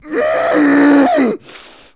1 channel
PAINLEG9.mp3